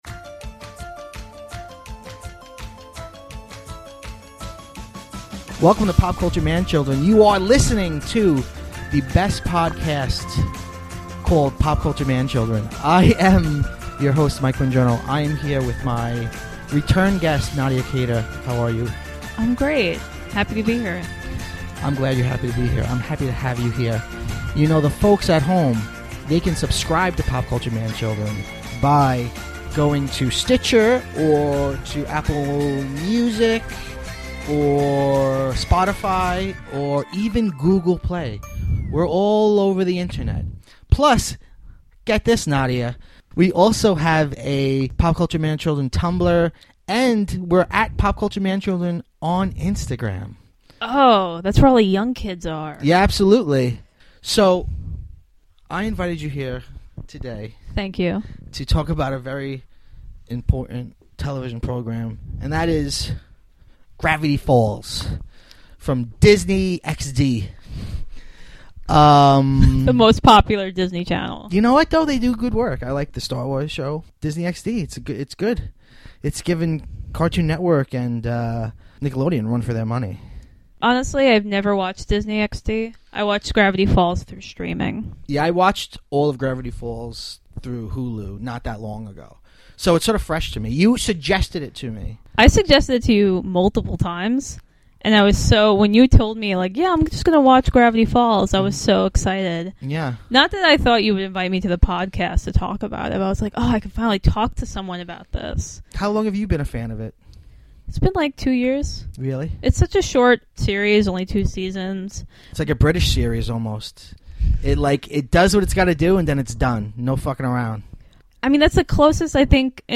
sit down to chat about the animated children’s series Gravity Falls.